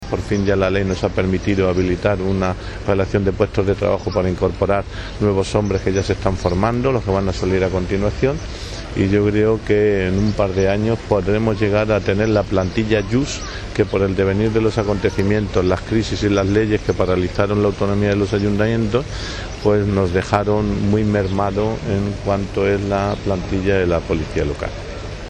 >> Lo ha adelantado el alcalde, Jesús Martín, durante los actos de la festividad del patrón del Cuerpo, Santo Ángel de la Guarda
cortealcaldeplantilla.mp3